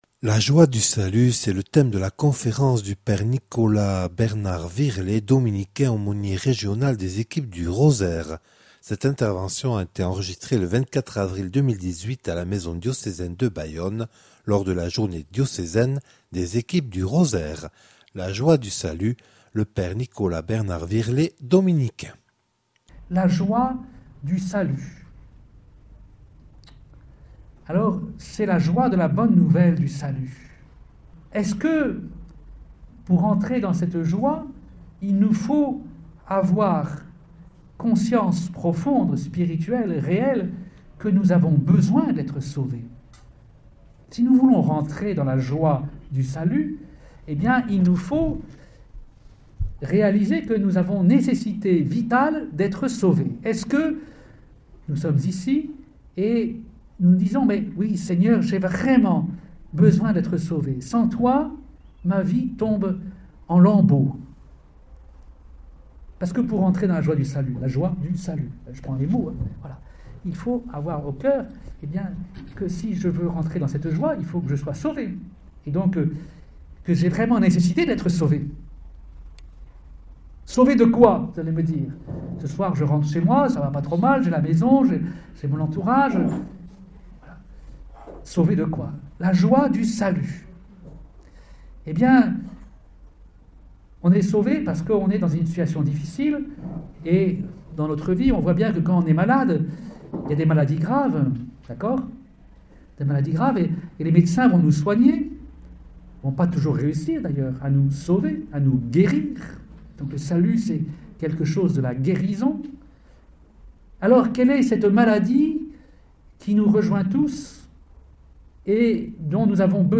Conférence
(Enregistrée le 24/04/2018 lors la journée diocésaine des Equipes du Rosaire à Bayonne).